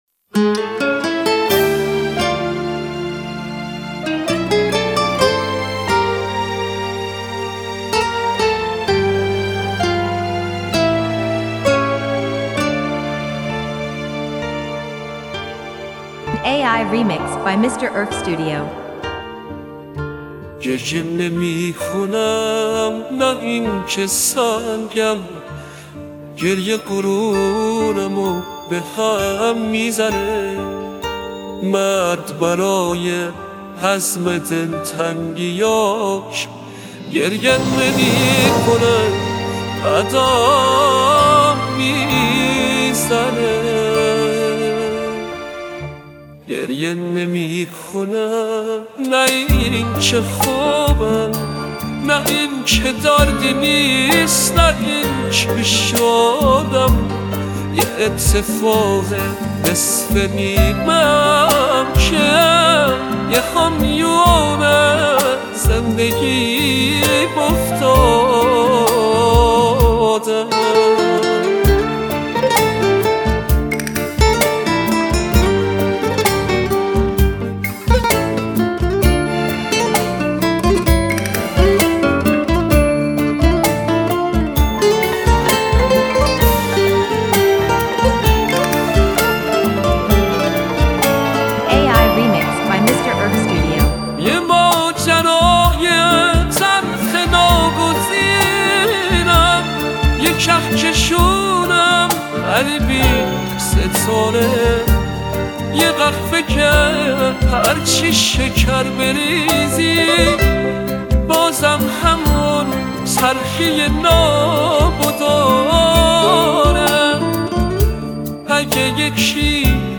اهنگ غمگین